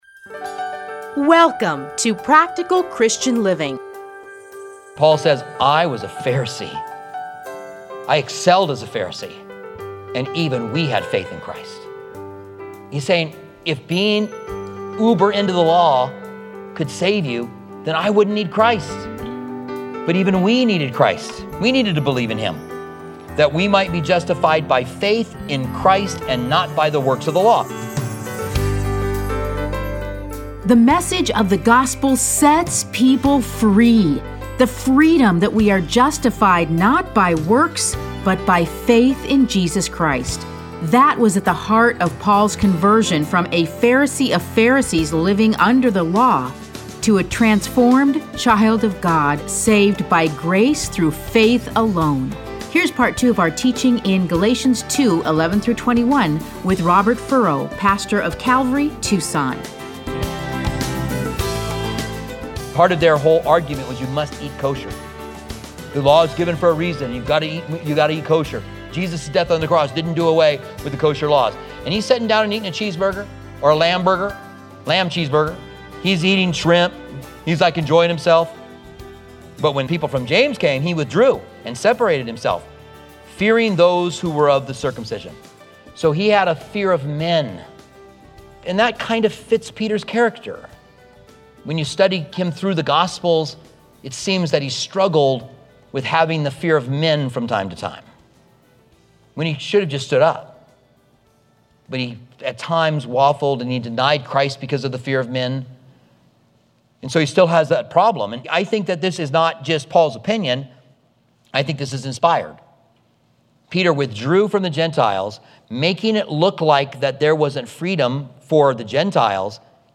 Listen to a teaching from Galatians 2:11-21.